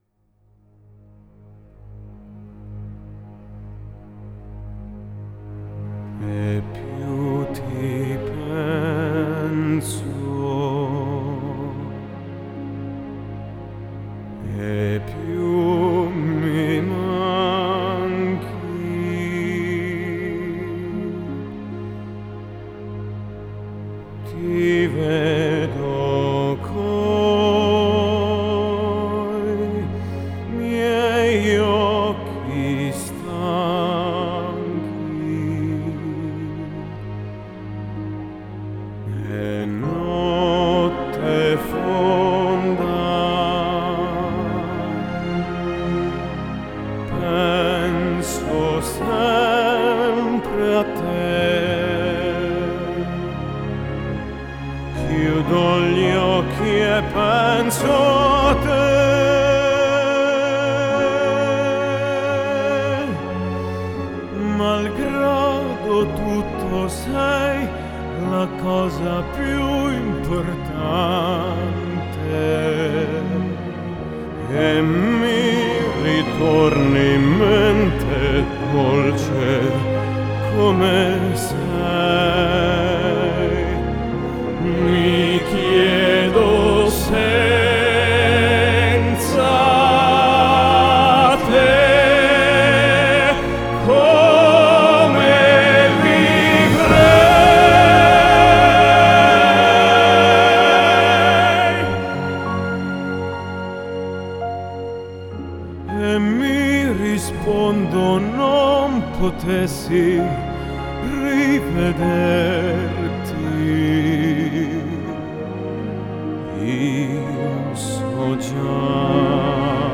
новое явление на поприще жанра классикал кроссовер.